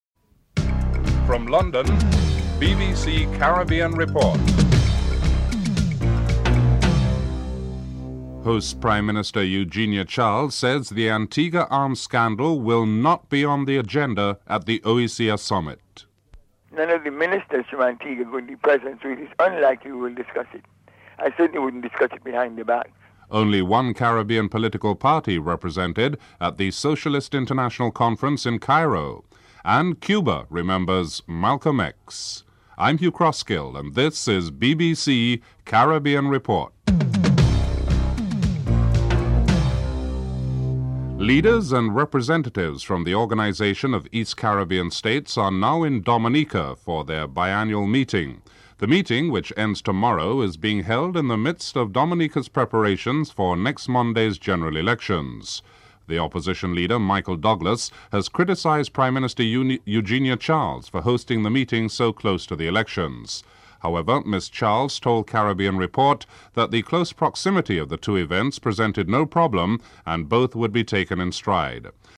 1. Headlines (00:00-00:40)
4. Financial News.